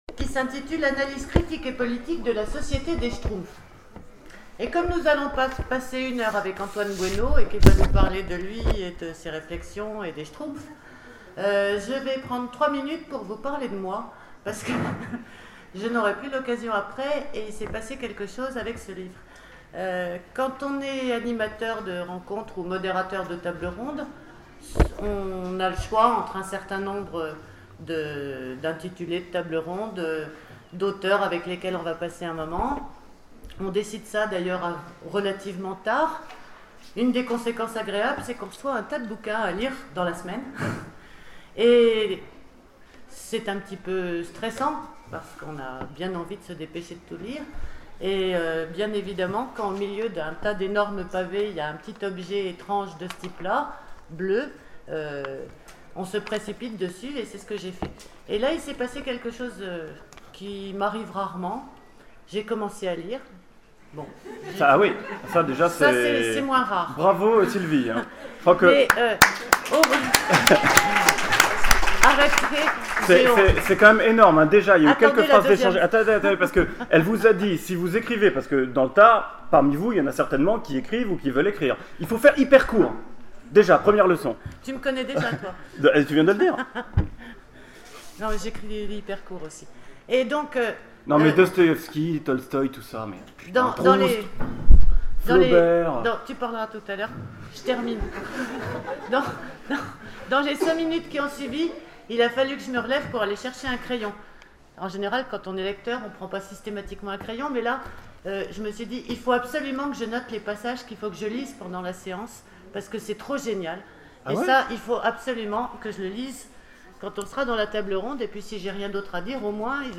Imaginales 2012 : Conférence Le grand Schtroumpf...